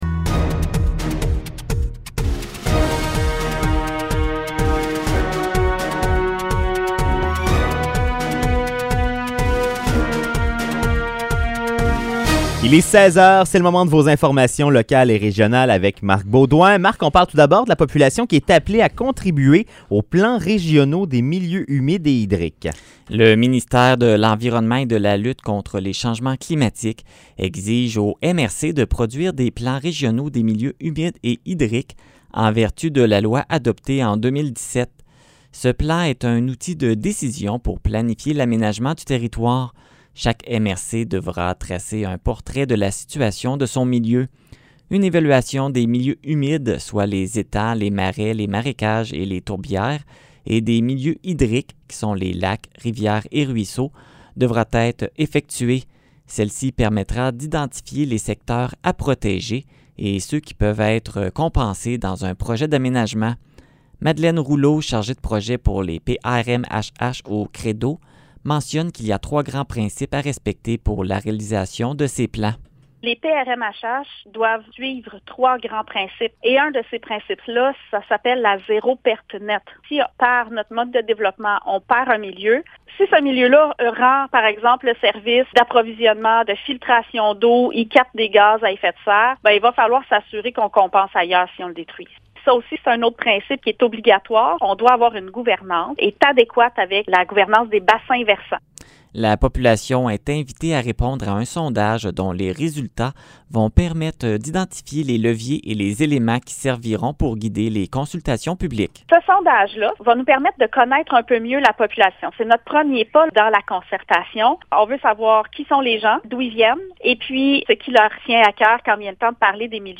Nouvelles locales - 18 mars 2021 - 16 h